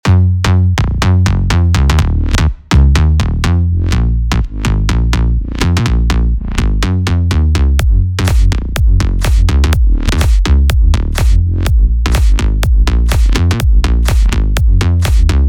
Inside, you’ll find all of the essentials from punchy basses, powerful drones, lush pads, and much more.